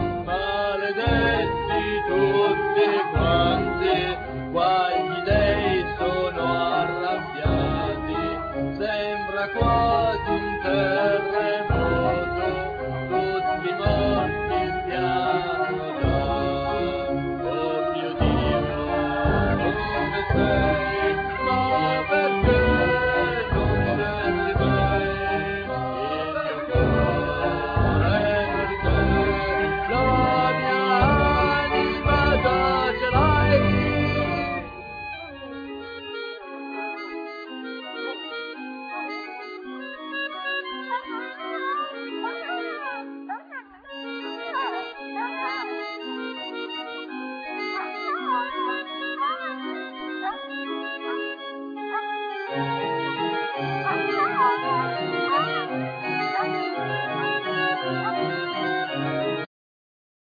Trombone
Basson
Trampet,Trampet Piccolo
Zarb,Viele,Timbales
Harp
Vocals
Chorus